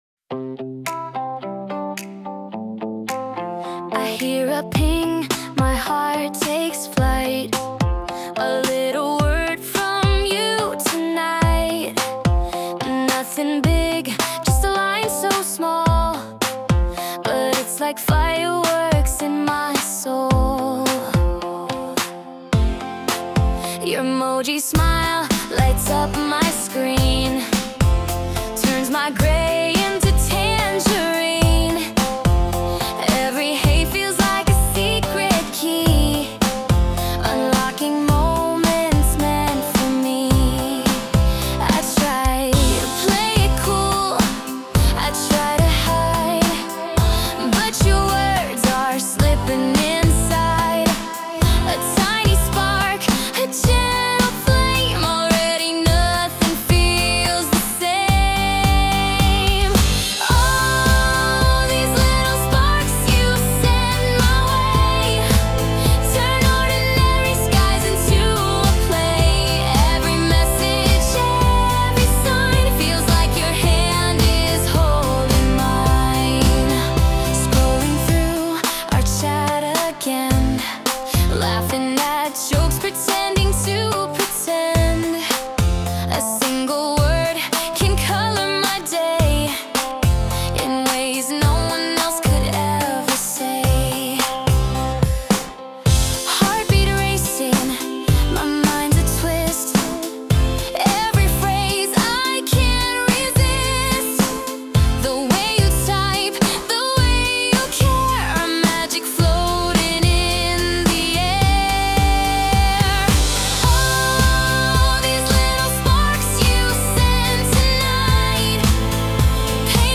洋楽女性ボーカル著作権フリーBGM ボーカル
著作権フリーオリジナルBGMです。
女性ボーカル（洋楽・英語）曲です。